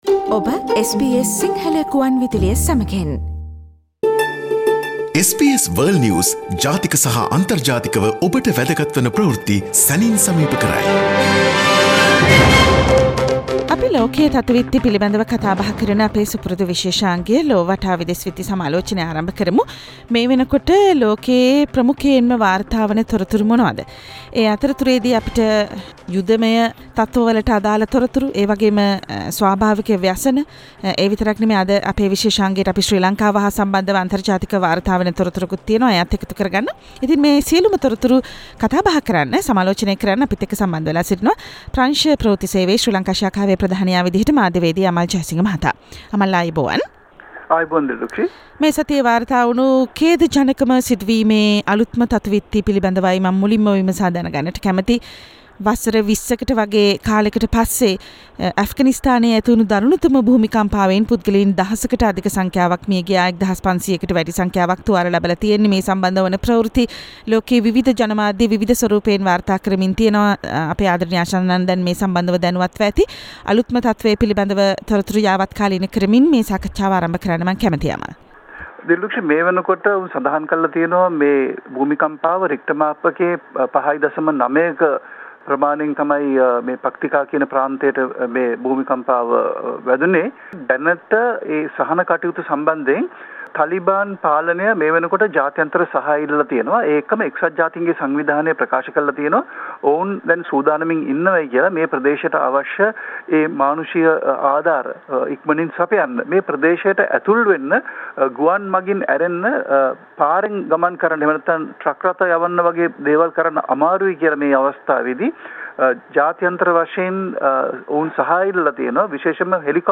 World's prominent news highlights in a few minutes - listen to SBS Si Sinhala Radio's weekly world News wrap on Friday